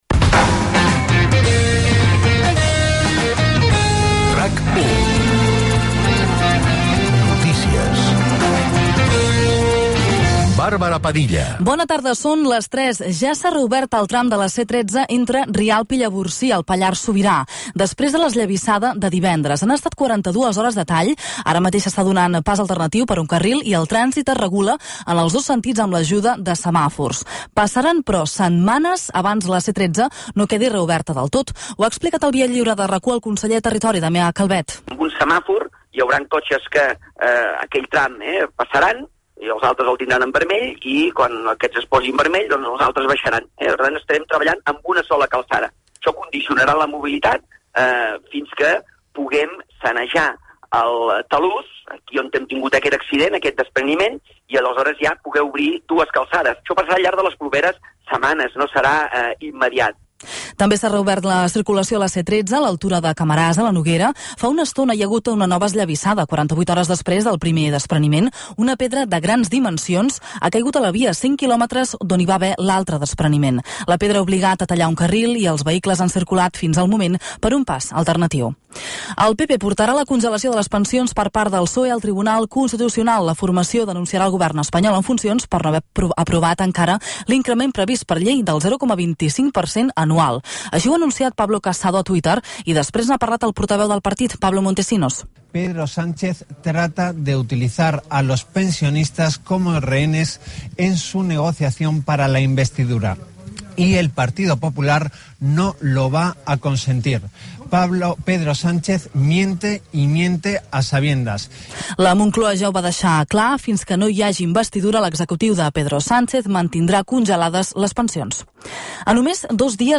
Careta del programa, reobertura del trànsit en un tram de la C-13 en un únic sentit, pensions, vendes del sorteig de la Grossa de Cap d'any, els Pastorets, el temps, música de sortida i publicitat
Informatiu